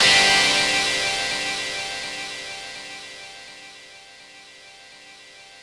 ChordGsus4.wav